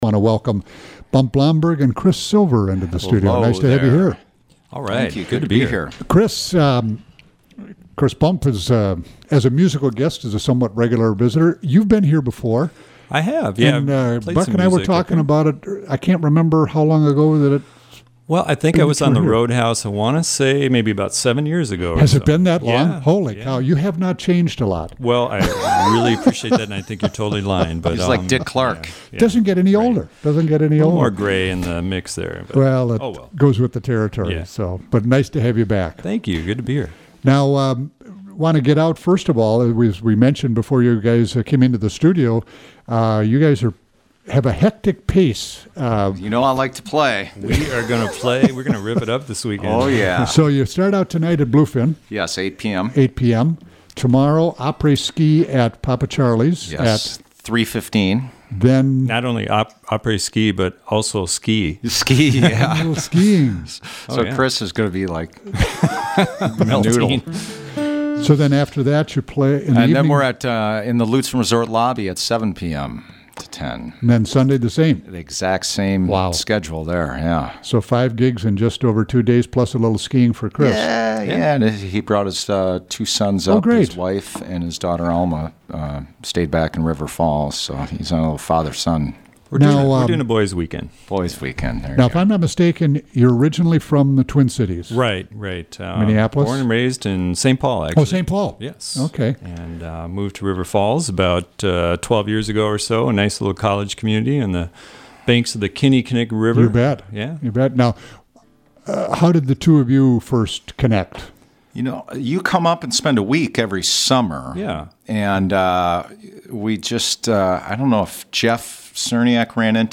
on guitar
on mandolin and resonator